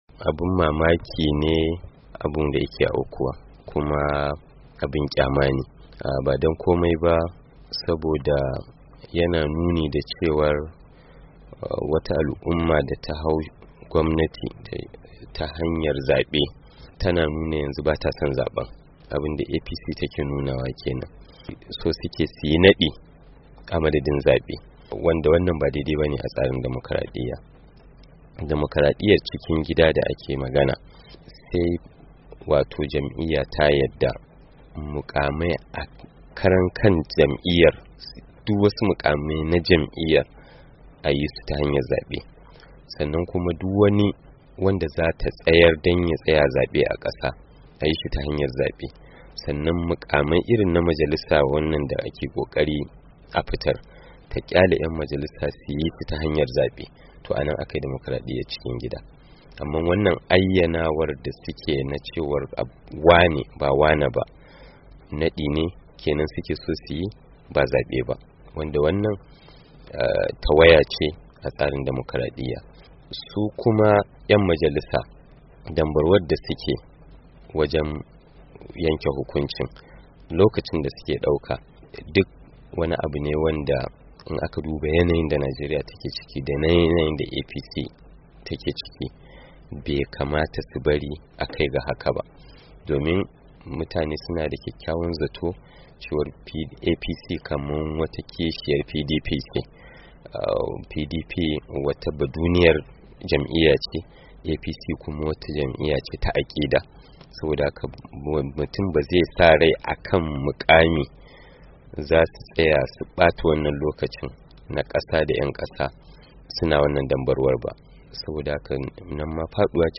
Ga cikakkiyar hirar: